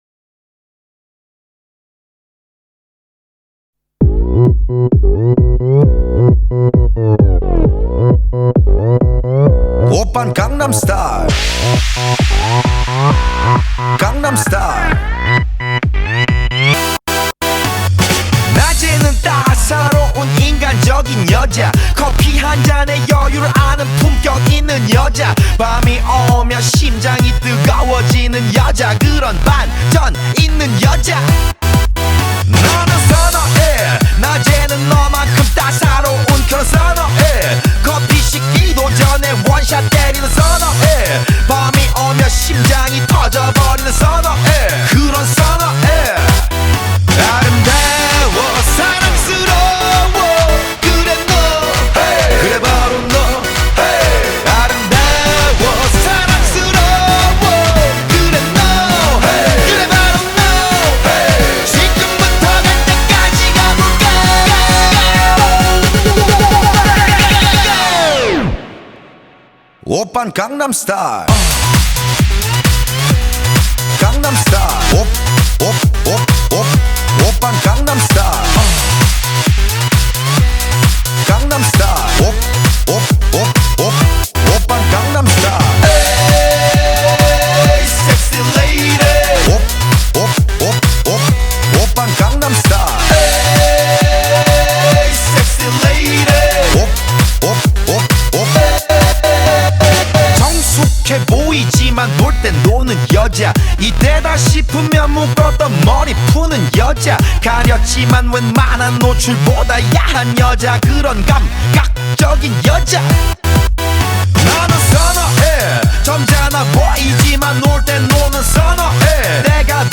• Audio doesn’t come in for about 4 seconds.
• The song ends and there’s a kind of “outro”.